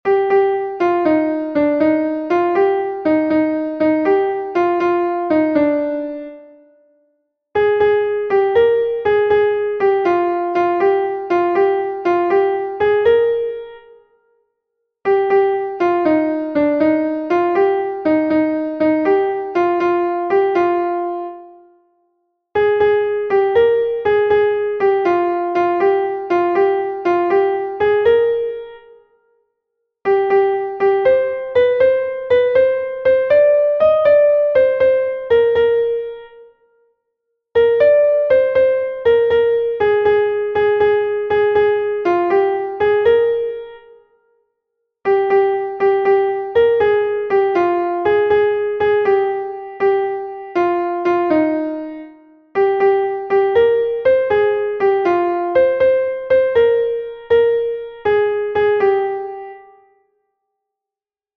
Hamabiko berdina, 6 puntuz eta 8 silabaz (hg) / Sei puntuko berdina, 16 silabaz (ip)
ABA1BCDEE1